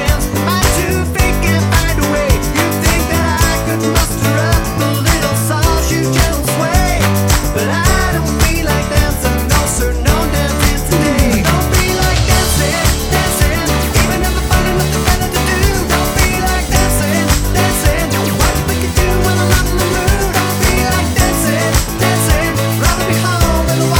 With Intro Pop (2000s) 4:26 Buy £1.50